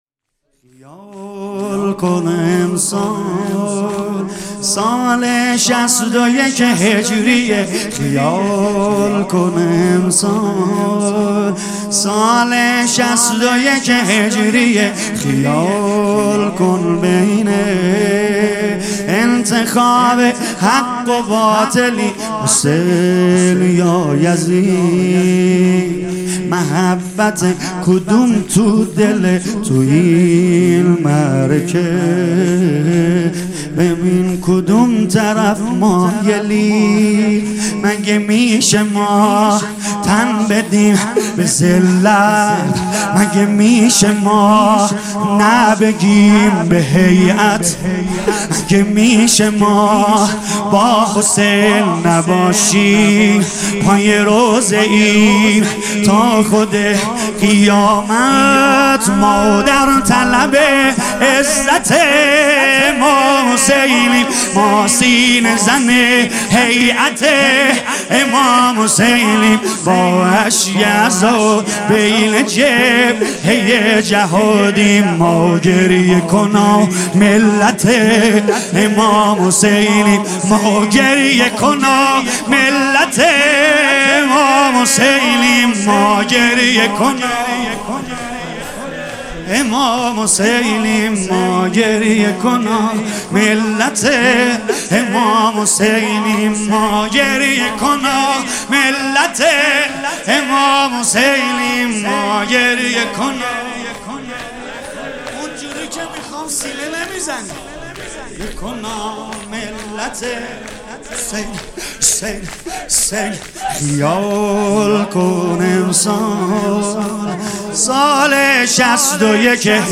مداحی جدید سید رضا نریمانی شب اول محرم 99.05.31 هیات فداییان حسین علیه السلام اصفهان
yeknet.ir_-_shoor_-_shabe_2_moharram1399_-_narimani.mp3